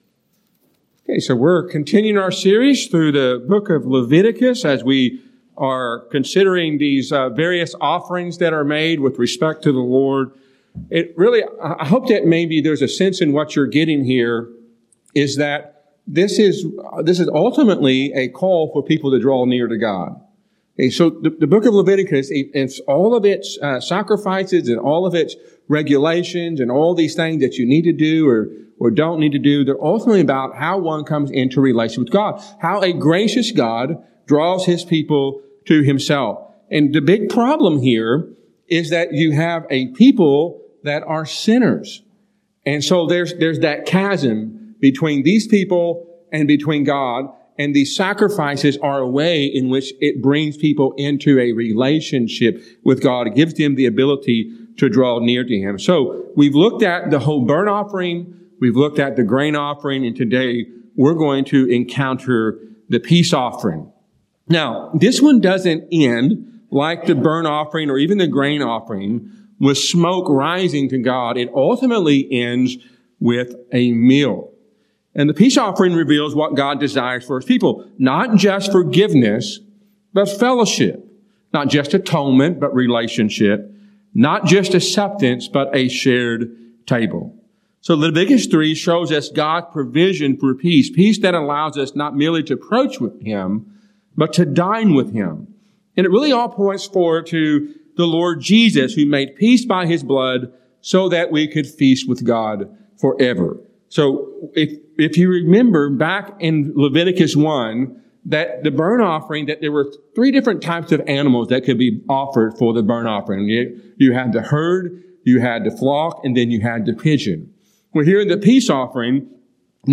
A sermon from Leviticus 3.